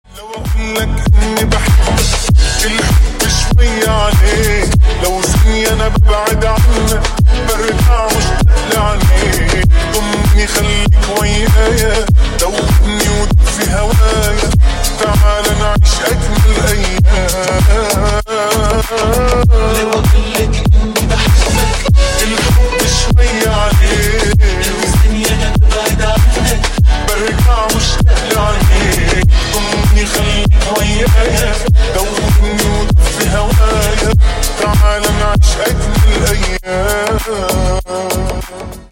Громкие Рингтоны С Басами » # Восточные Рингтоны
Танцевальные Рингтоны